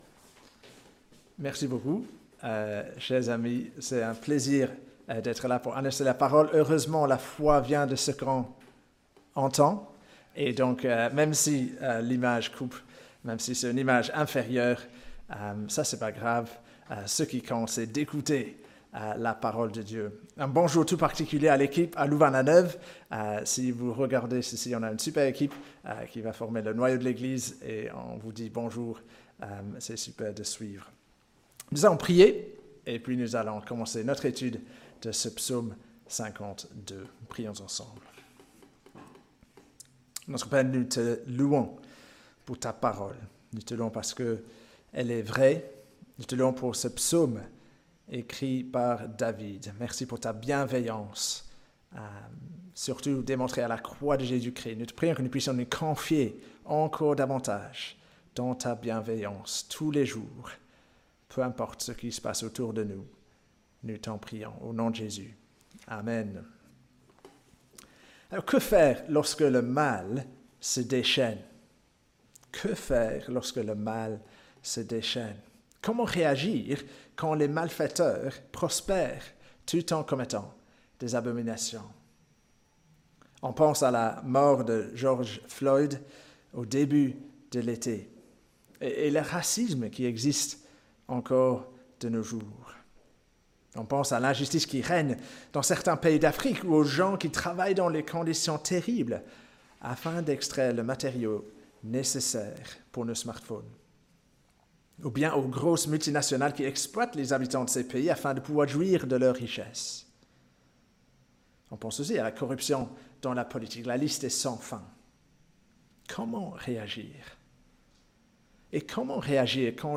culte-du-2-aout-epe-bruxelles-woluwe.mp3